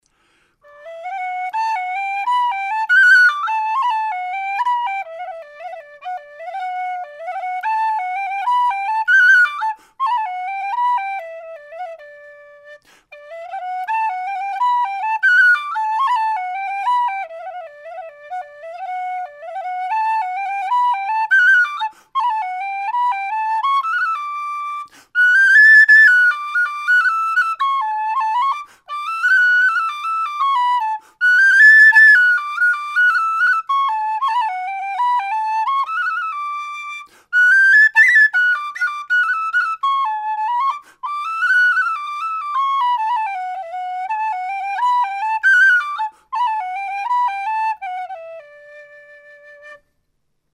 D Whistles
made out of thin-walled aluminium tubing with 13mm bore